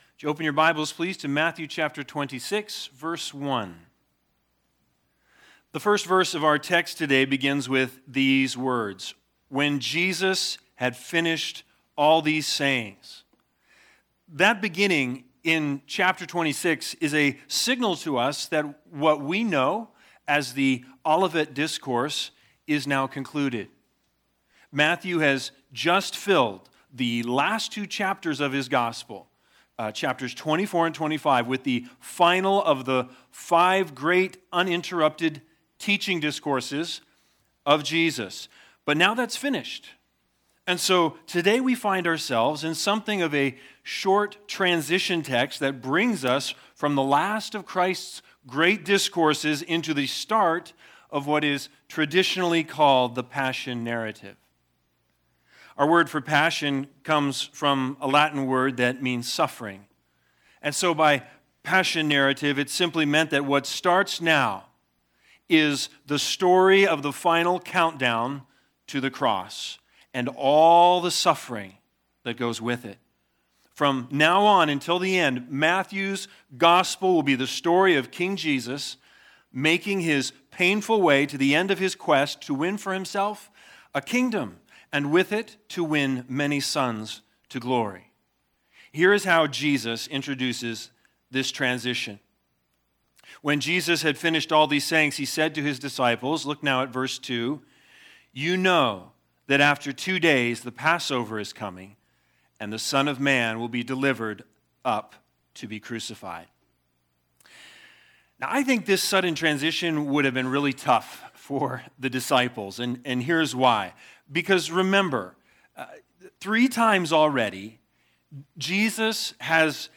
Matthew 26:1-5 Service Type: Sunday Sermons BIG IDEA